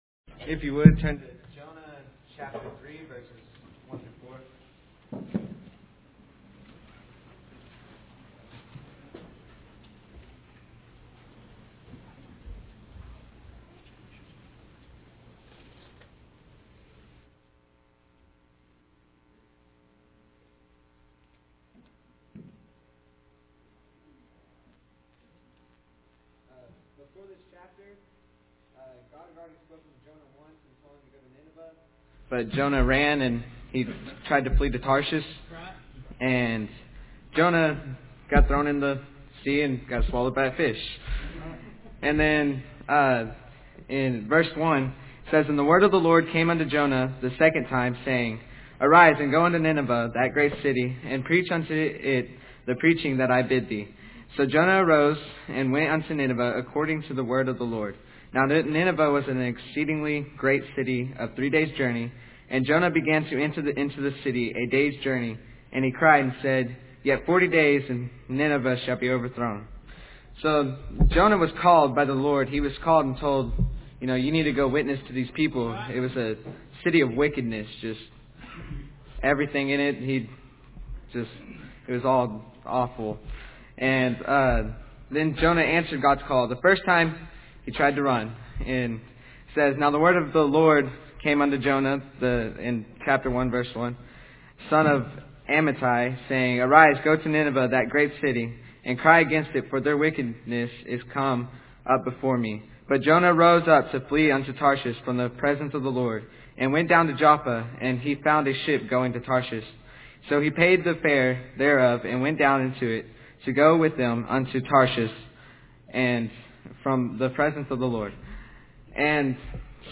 110412PM - Youht-Led Service